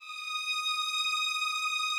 strings_075.wav